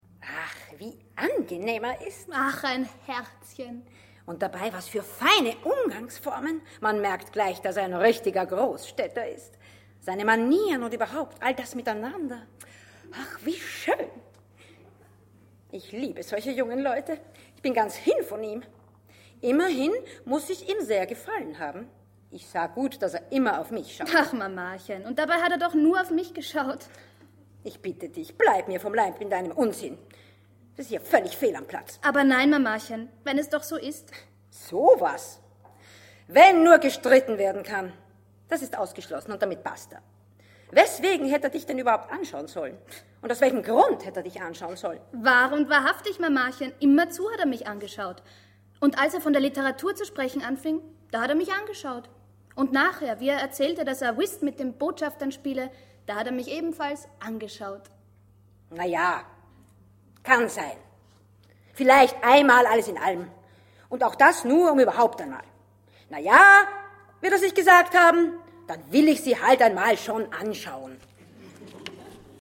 Hörprobe: „Der Revisor“ (Nikolai Gogol)